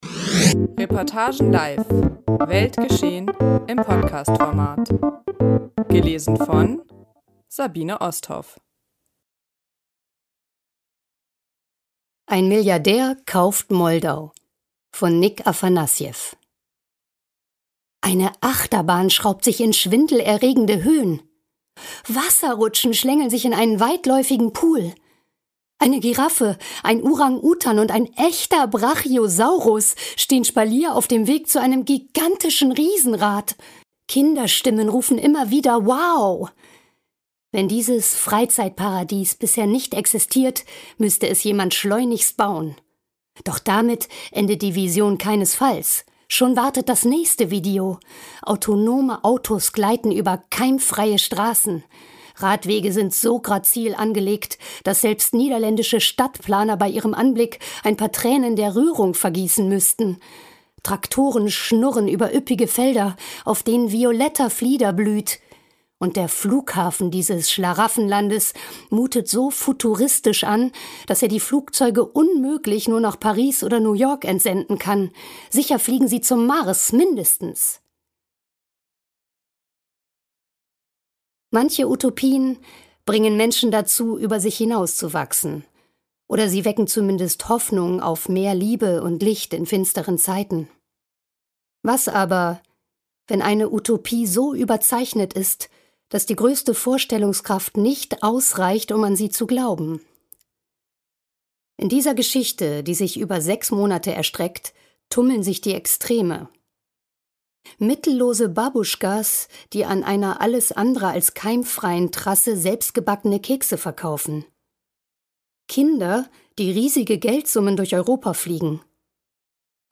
Ein Milliardär kauft Moldau ~ Podcast Reportagen live.